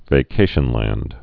(vā-kāshən-lănd)